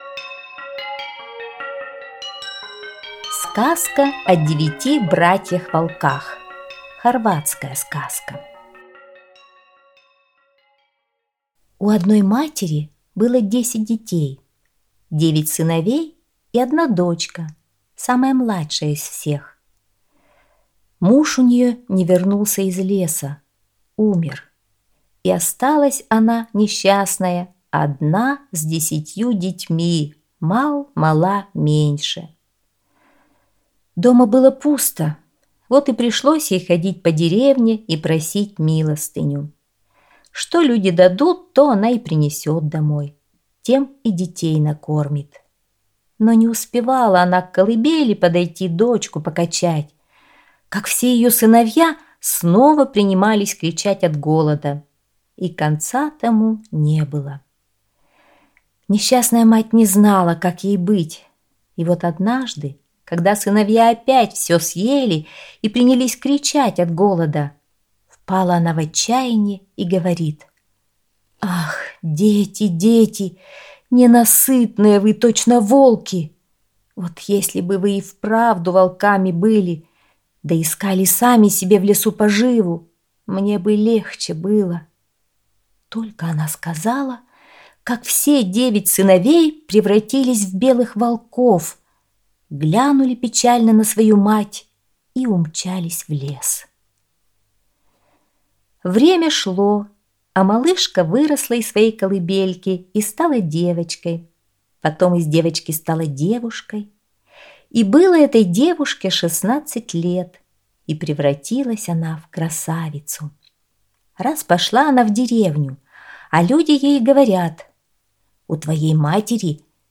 Сказка о девяти братьях-волках - хорватская аудиосказка